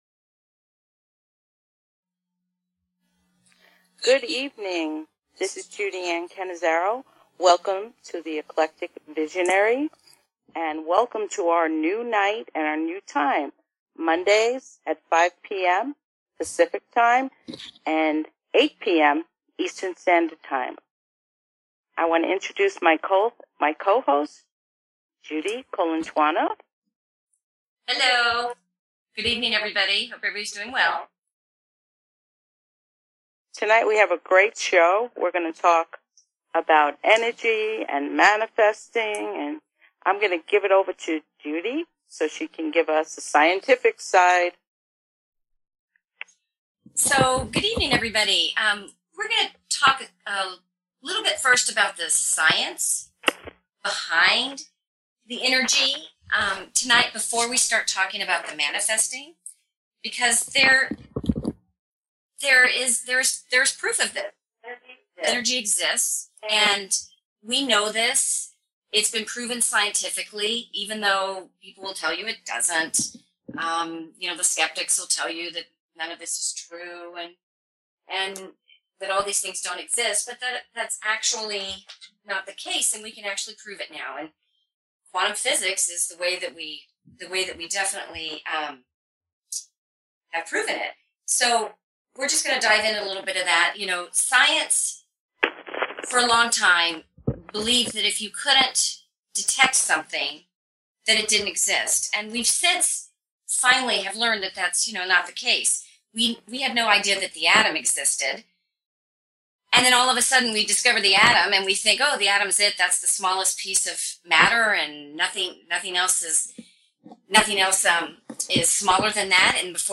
Come join us for an hour of interesting, informative and enlightening topics with amazing guests.